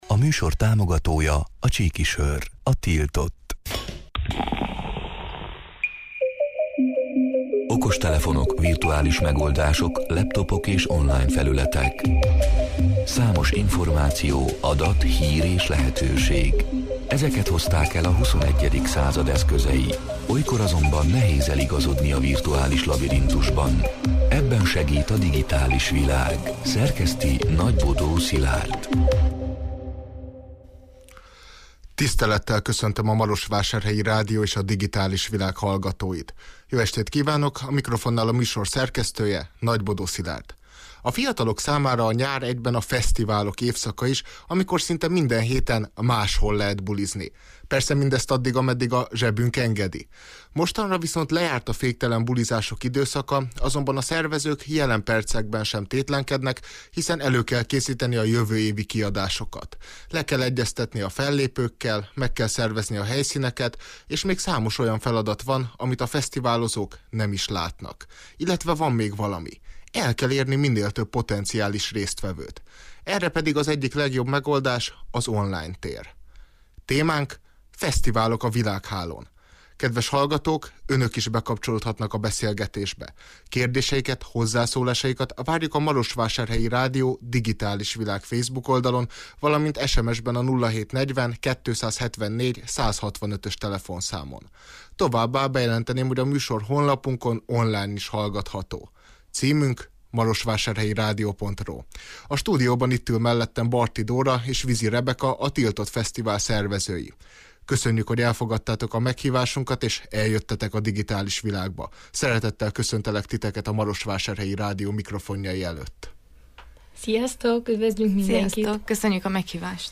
A Marosvásárhelyi Rádió Digitális Világ (elhangzott: 2022. szeptember 27-én, kedden este nyolc órától élőben) c. műsorának hanganyaga: A fiatalok számára a nyár egyben a fesztiválok évszaka is, amikor szinte minden héten máshol lehet bulizni.